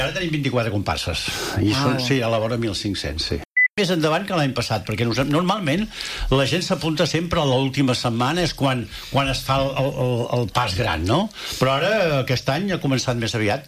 La comissió organitzadora ha avançat en una entrevista a l’FM i + que l’Associació de Teatre Solidari (Atsoc) ha preparat una sorpresa que es podrà veure a la capçalera de la desfilada.